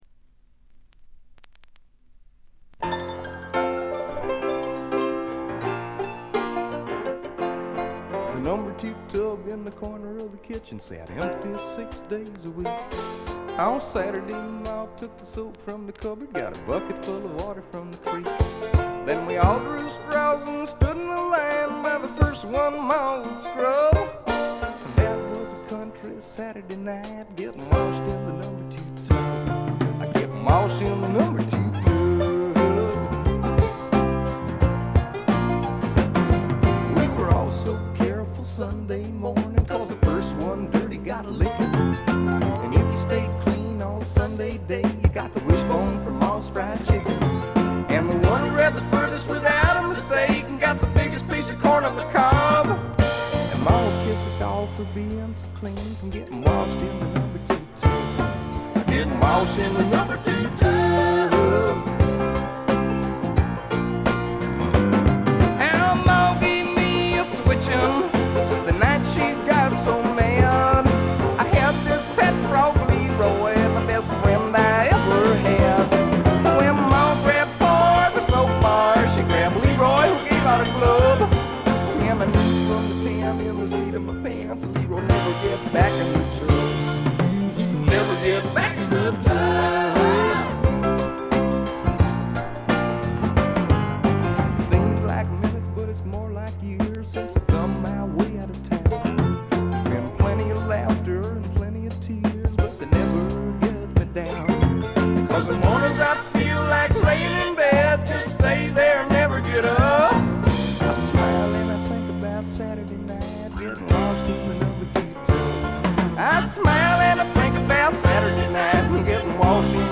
特別に目立つノイズはありません。
西海岸製スワンプ系シンガーソングライターの裏名盤です。
試聴曲は現品からの取り込み音源です。